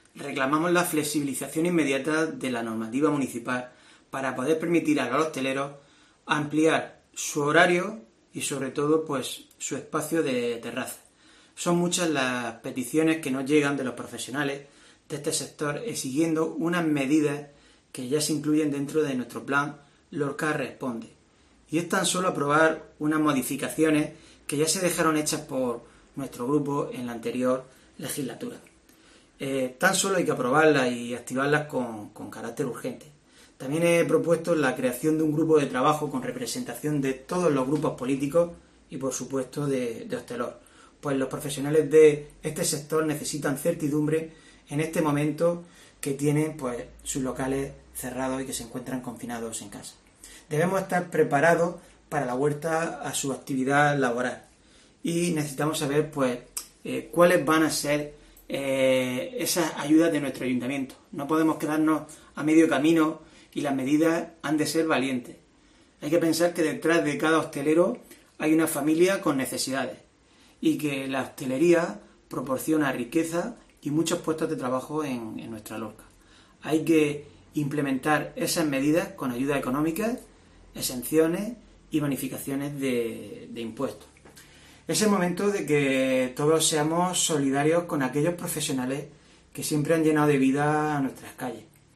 Francisco Javier Martínez, edil del PP en Lorca sobre medidas para bares y terrazas